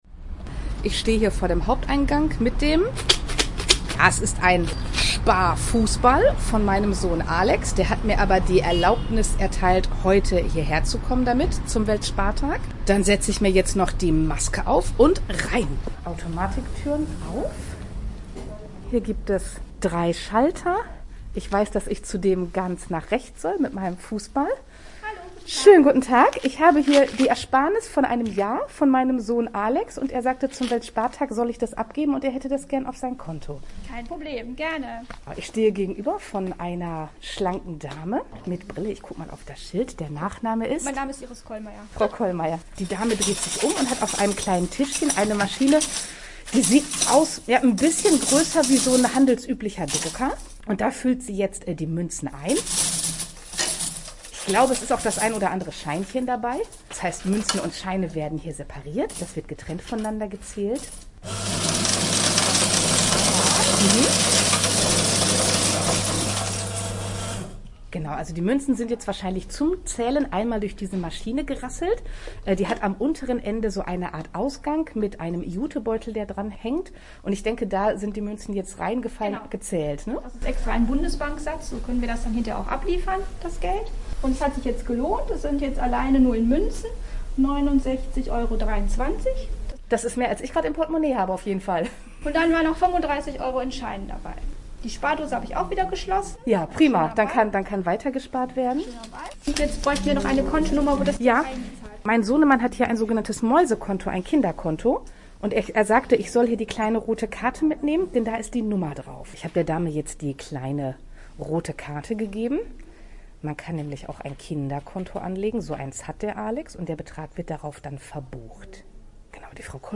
Sparkassentag-Reportage-Weltsparttag-T.mp3